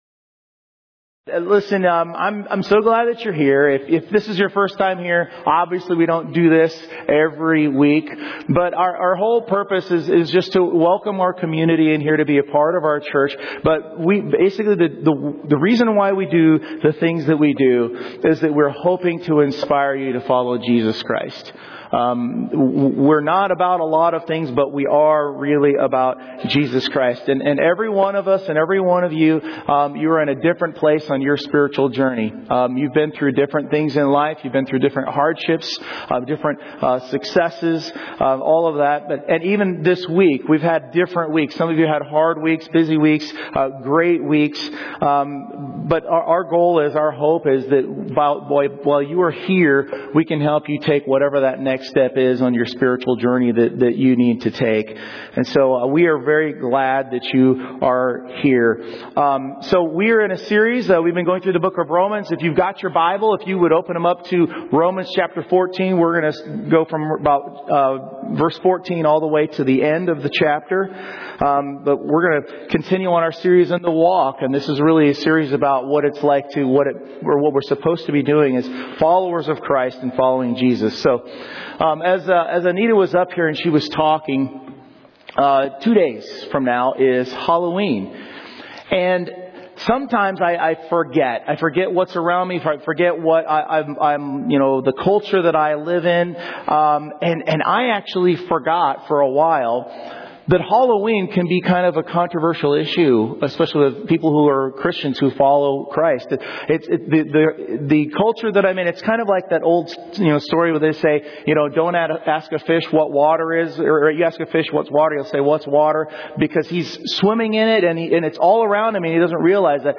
2023 Sermons